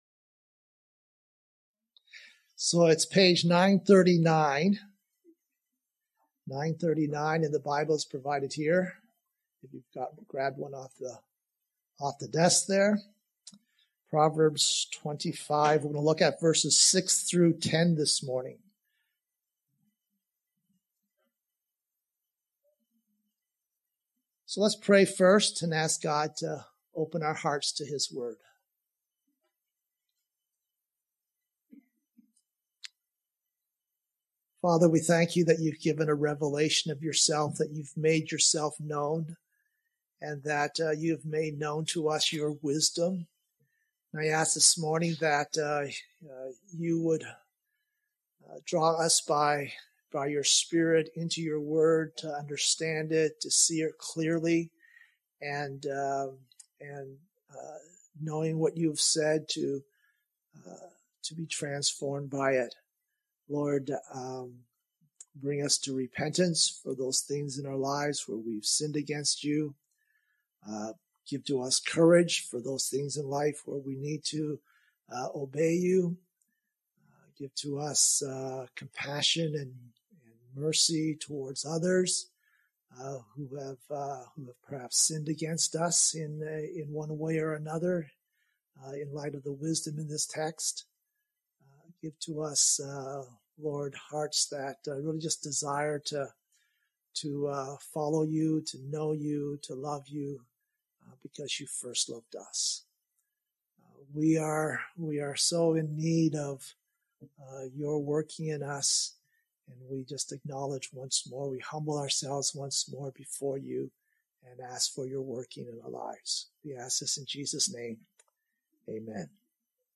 2025 Power Turned to Shame Preacher